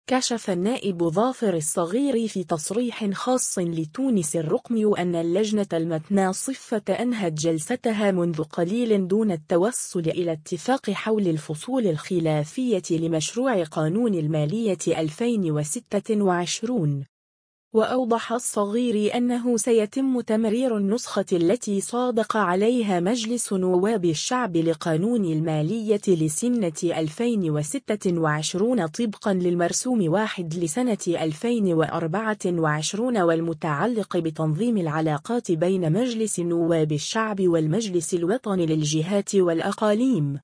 كشف النائب ظافر الصغيري في تصريح خاص لـ”تونس الرقميو” أنّ اللجنة المتناصفة أنهت جلستها منذ قليل دون التوصل إلى اتفاق حول الفصول الخلافية لمشروع قانون المالية 2026.